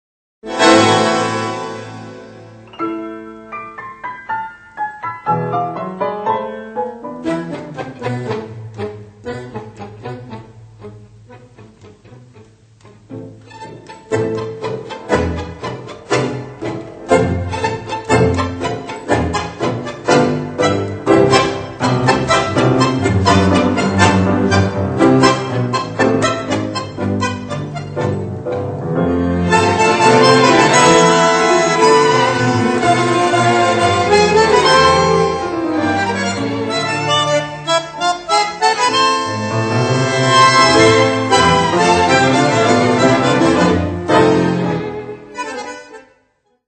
Klassische Tangos